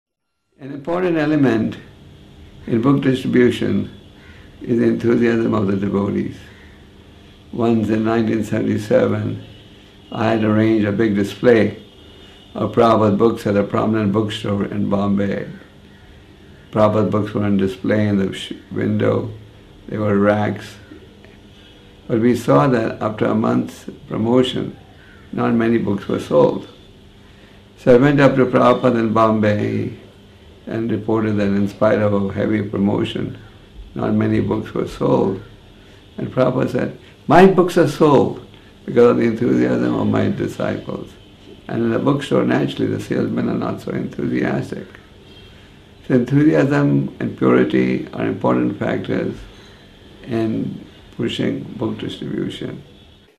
Posted on Apr 16, 2014 in Book Distribution Audio Lectures